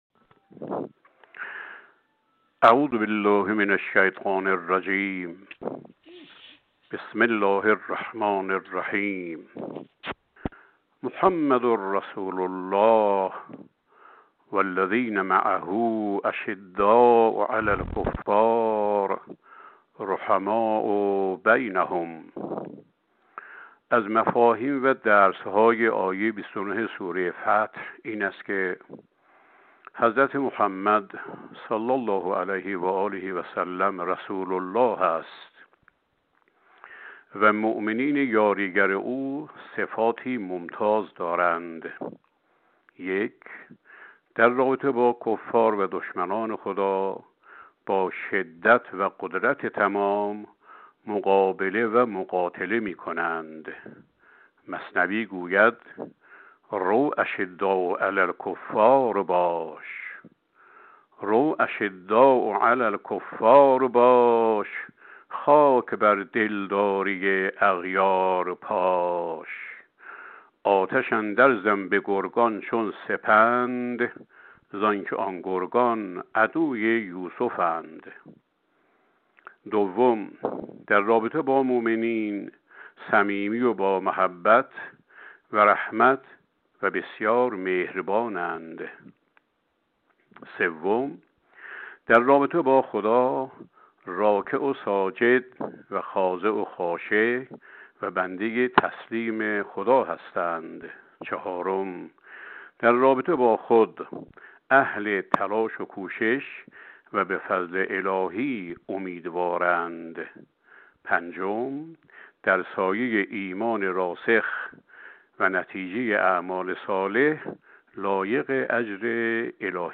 پیشکسوت قرآنی کشورمان در گفت‌وگو با خبرنگار ایکنا به بیان توضیحاتی در مورد عملیات «وعده صادق» پرداخت که از سوی نیروهای مسلح جمهوری اسلامی ایران علیه رژیم اشغالگر قدس صورت گرفت.